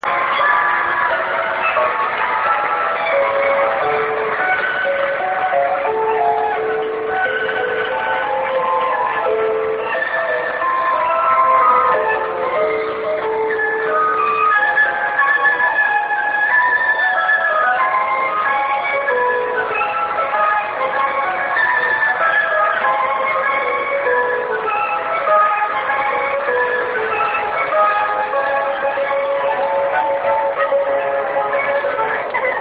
je ne parviens pas à identifier une oeuvre pour violon...
voici un extrait de piètre qualité (je suis désolé je n'ai que ça)